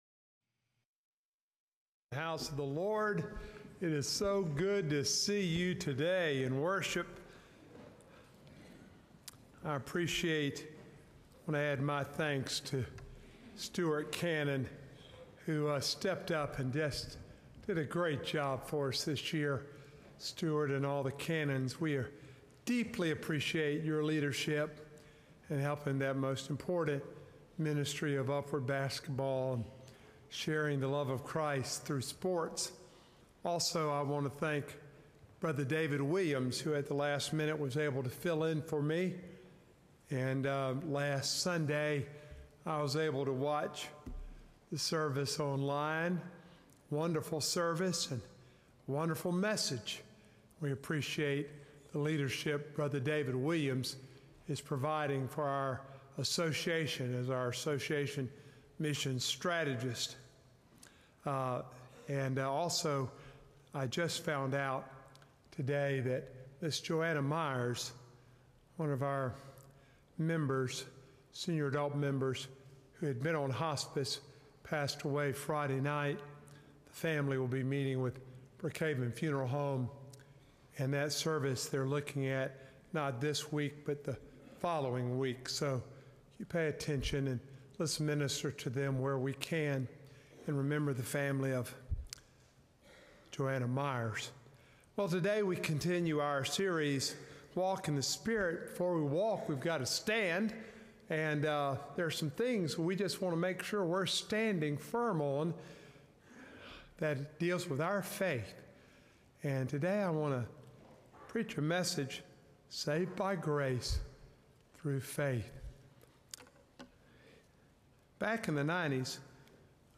Sermons
February-9-2025-Sermon-Audio.mp3